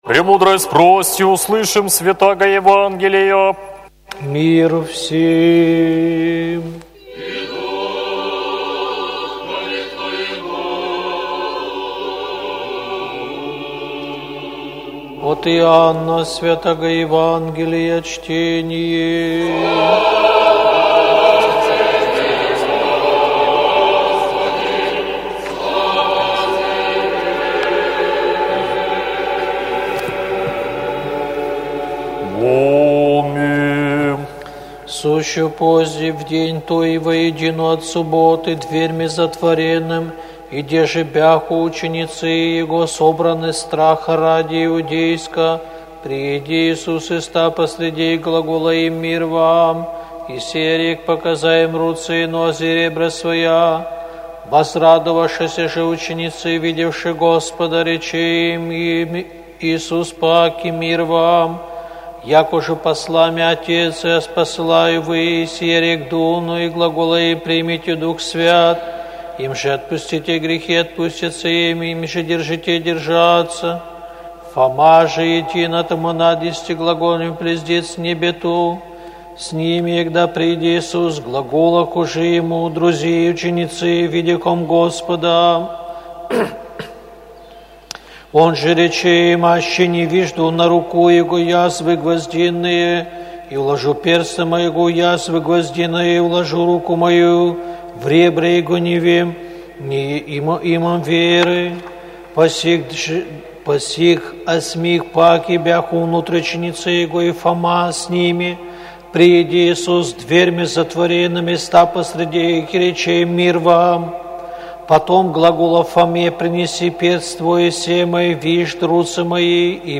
ЕВАНГЕЛЬСКОЕ ЧТЕНИЕ НА УТРЕНЕ